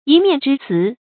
一面之詞 注音： ㄧ ㄇㄧㄢˋ ㄓㄧ ㄘㄧˊ 讀音讀法： 意思解釋： 單方面的言辭；即某一個方面的意見。